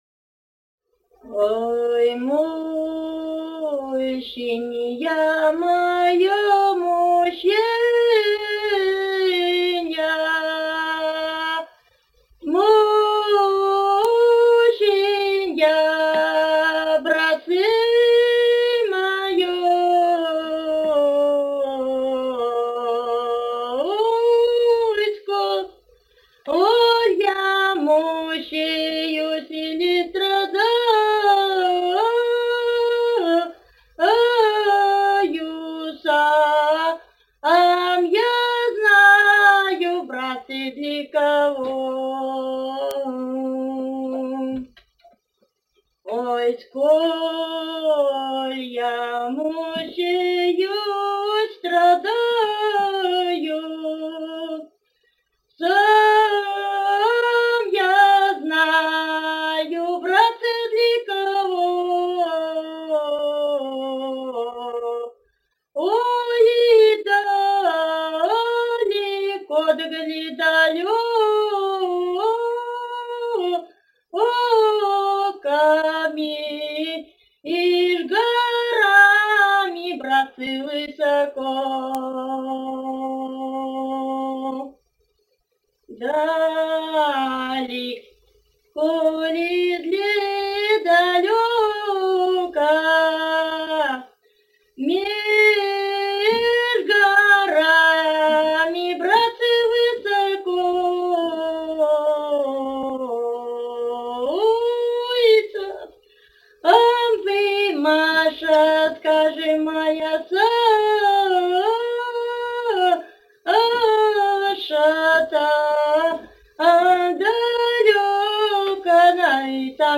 Русские песни Алтайского Беловодья «Ой, мученья моё, мученья», лирическая.